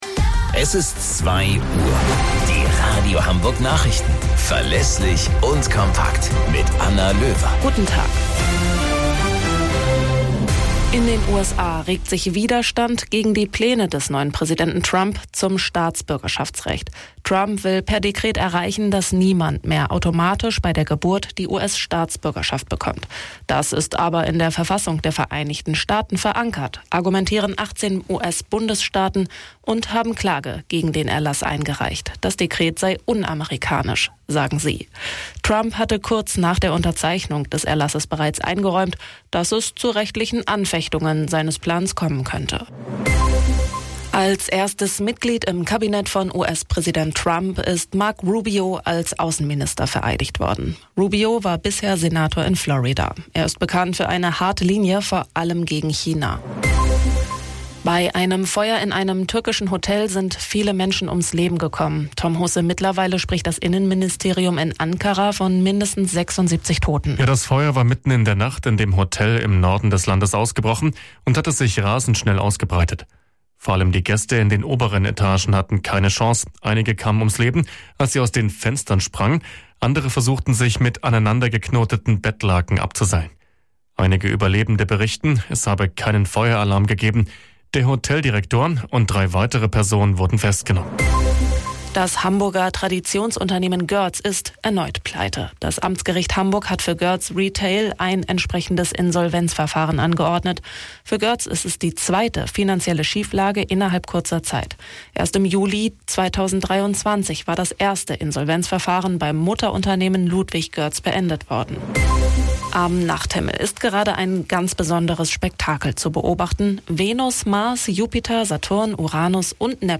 Radio Hamburg Nachrichten vom 22.01.2025 um 06 Uhr - 22.01.2025